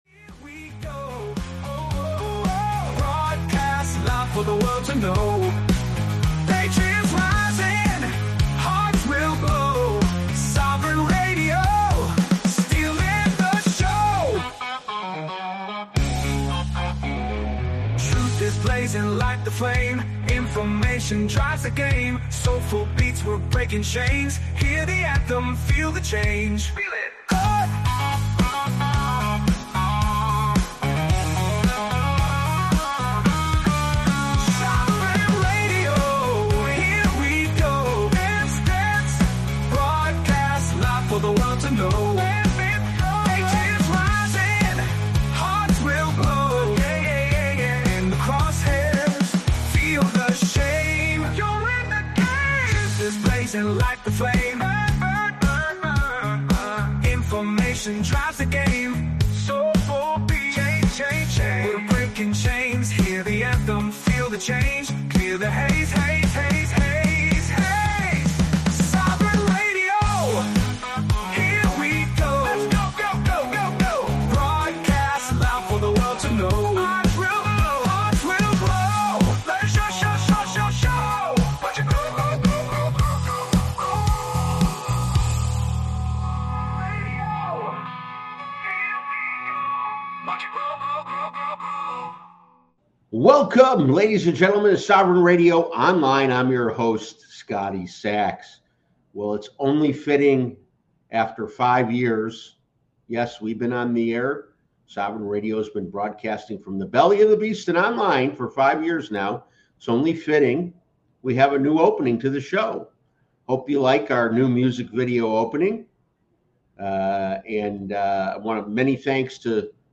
The show invites guests to discuss finance, constitutional rights, global politics, and media. Broadcasted online and on various radio stations, it reaches listeners worldwide, offering diverse perspectives on important issues.